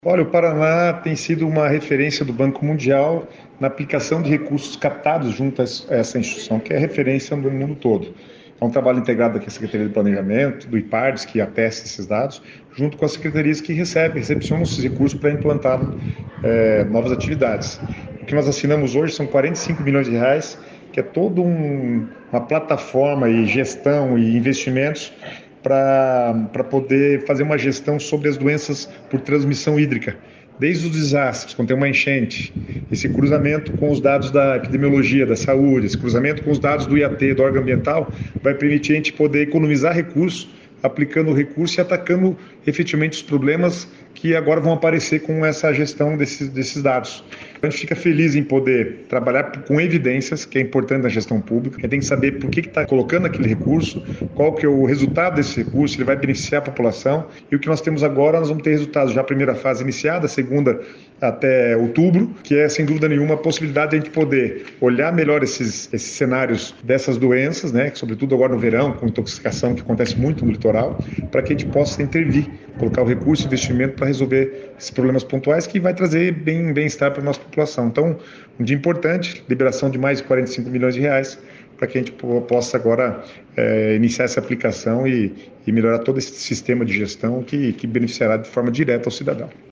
Sonora do secretário do Planejamento, Guto Silva, sobre a solicitação ao Banco Mundial de R$ 45 milhões para ações do Paraná Eficiente